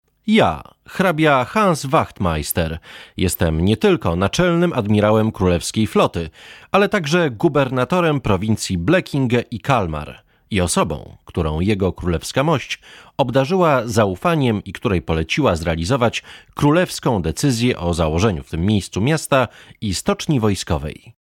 Informative 2